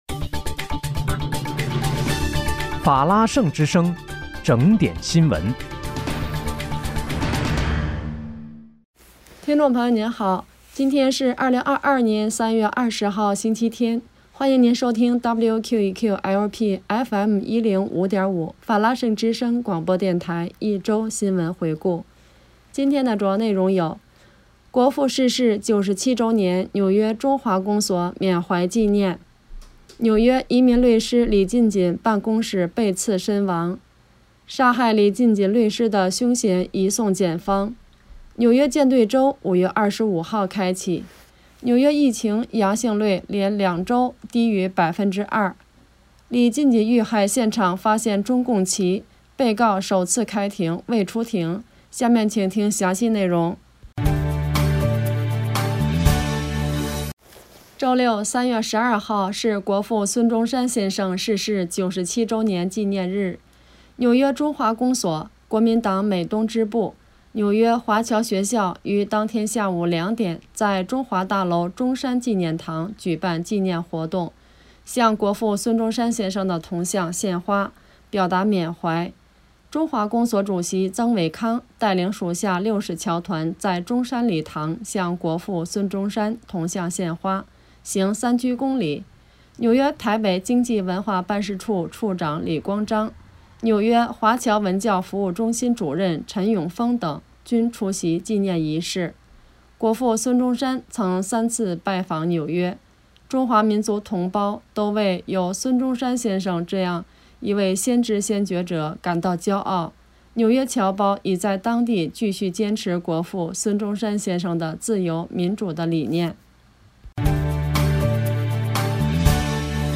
3月20日（星期日）一周新闻回顾